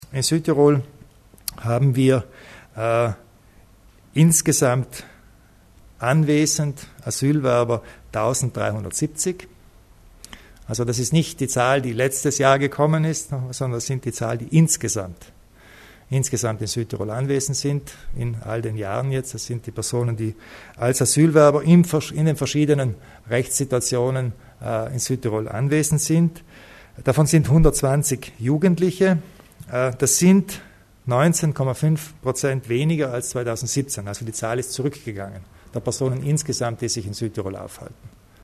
Landeshauptmann Kompatscher zur Anzahl der Flüchtlinge in Südtirol
Politik | 17.07.2018 | 17:11 Migrationspolitik: LH Kompatscher spricht von Fortschritten Knapp 20 Prozent weniger Asylwerber in Südtirol, ein Rückgang der Ankünfte in Italien um 81 Prozent und eine europäische Lösungssuche stimmten zuversichtlich. Auf das Thema Migration ging Landeshauptmann Arno Kompatscher bei der heutigen (17. Juli) Pressekonferenz nach der Sitzung der Landesregierung vor dem Hintergrund des EU-Innenministergipfels in Innsbruck in der vergangenen Woche ein.